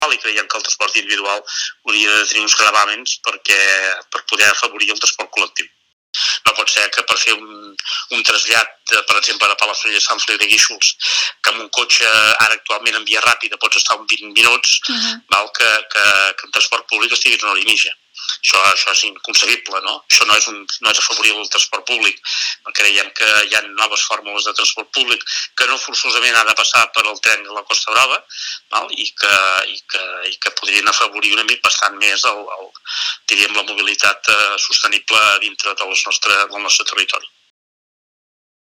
Finalment, també hem parlat amb Josep Piferrer, alcalde d’un altre dels grans municipis turístics del Baix Empordà, Palafrugell. Piferrer explica que tampoc esperen que la lliure circulació comporti cap gran canvi.